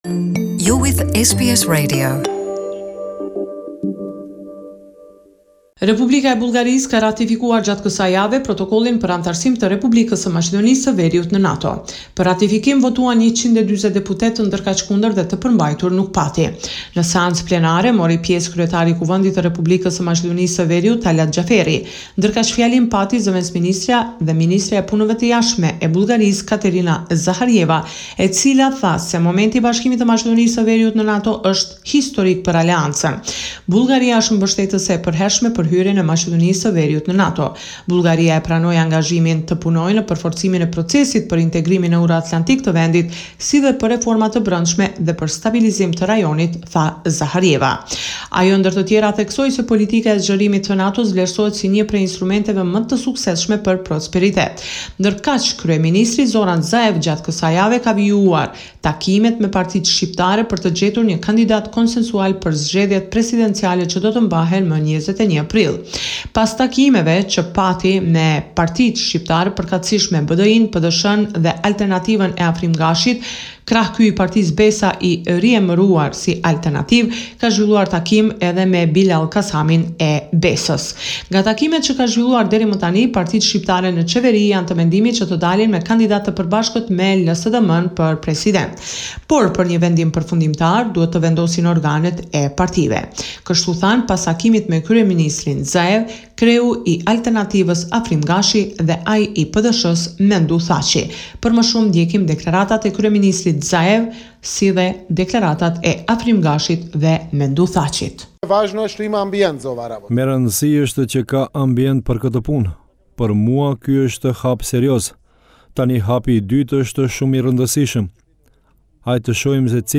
This is a report summarising the latest developments in news and current affairs in Macedonia.